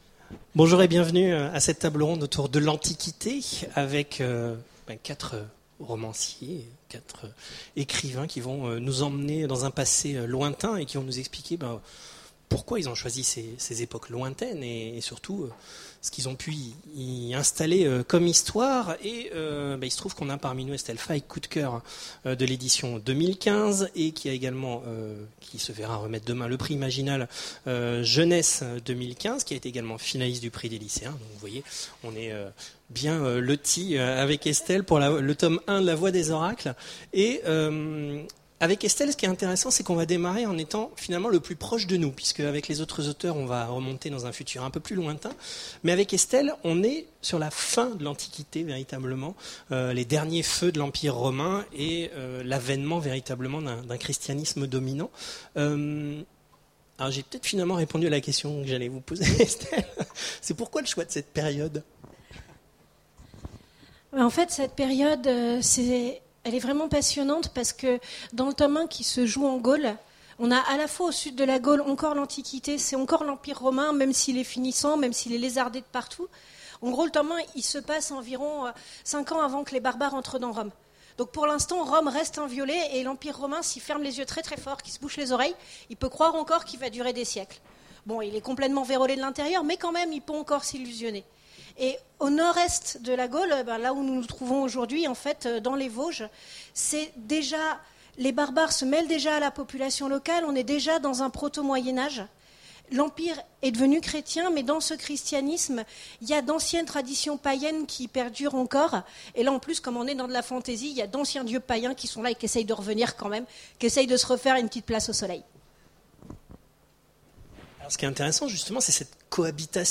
Imaginales 2015 : Conférence Faire revivre l'Antiquité